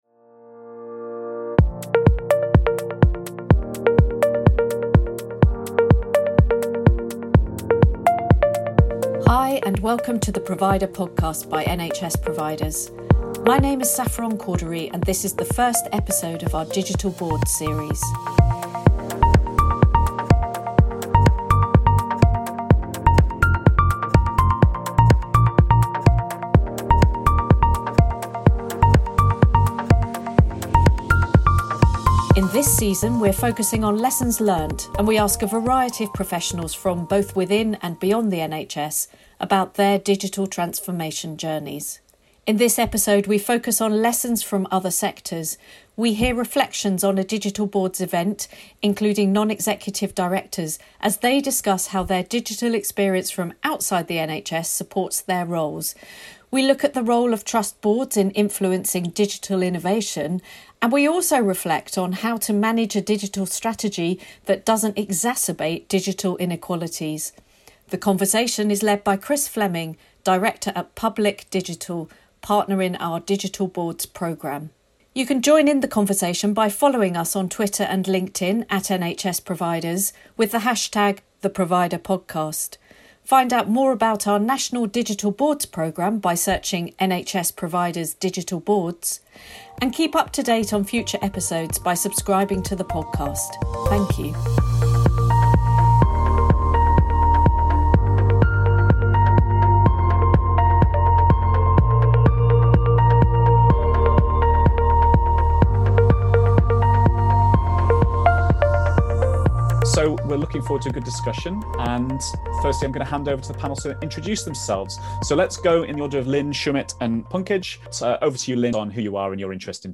We speak to three non-executive directors about lessons learnt from other sectors for digital transformation, which can help shape the NHS' digital journey. This recording was taken from a live virtual Digital Boards event earlier in 2021